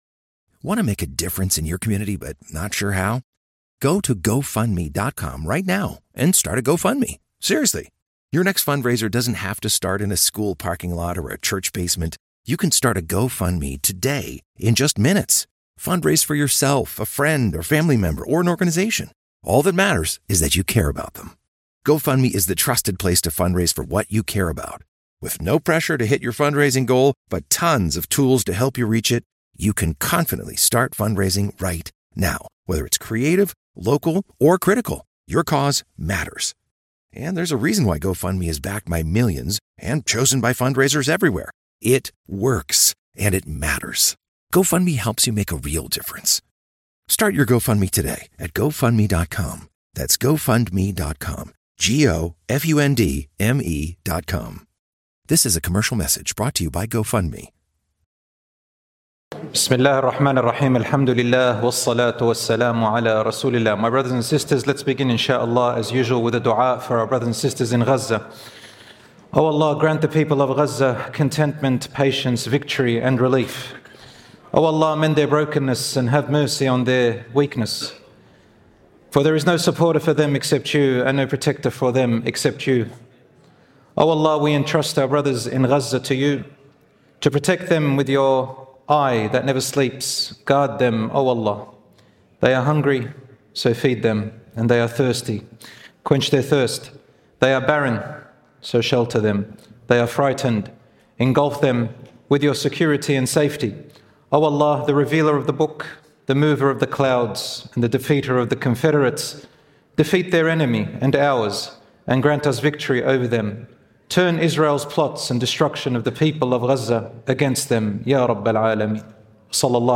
In this lecture, we uncover the hidden dangers of envy, jealousy, and the evil eye, how they creep into our hearts, damage our lives, and the powerful remedies Allah has given us to fight back.